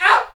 Index of /90_sSampleCDs/Roland L-CD701/PRC_FX Perc 1/PRC_Ping Pong